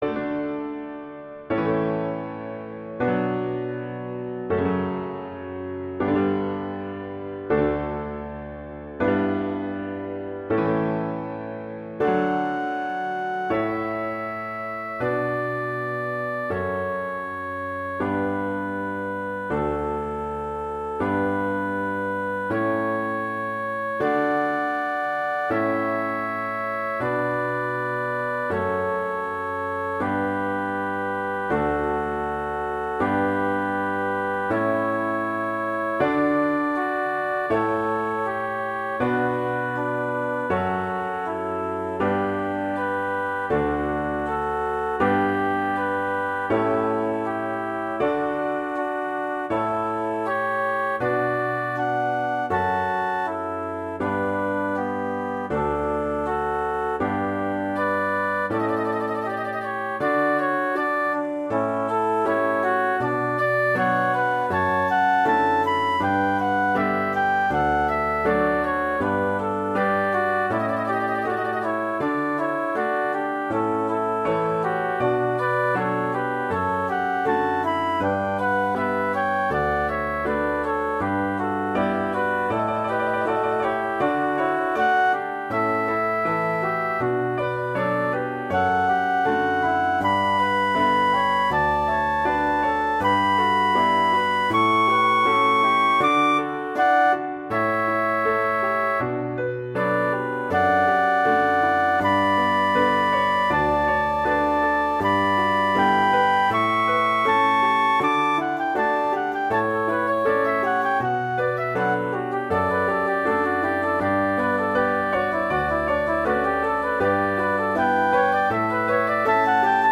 classical, wedding, traditional, easter, festival, love
D major